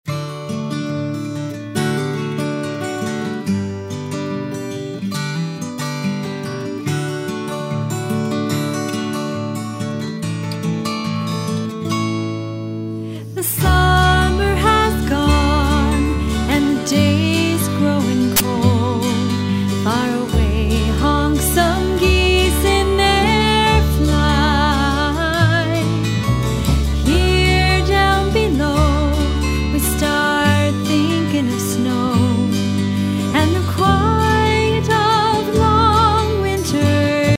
acoustic bass
Appalachian dulcimer
fiddle